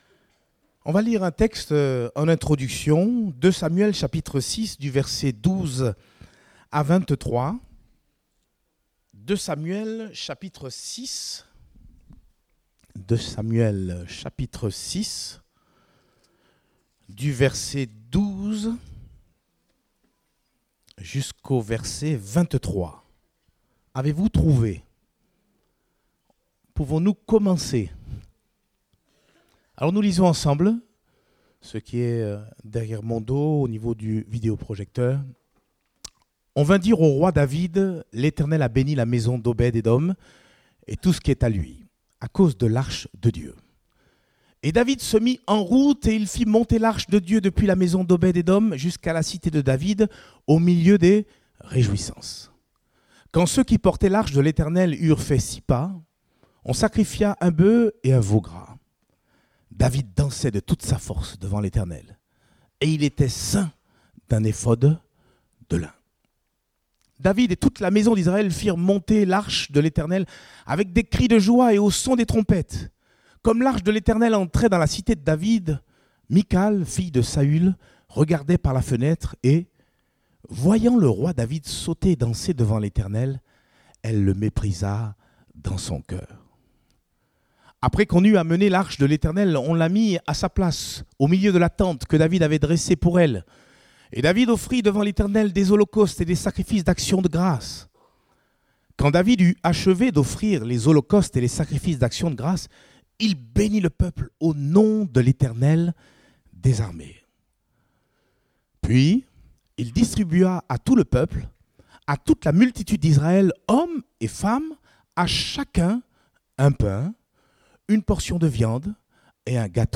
Culte Dominical Prédicateur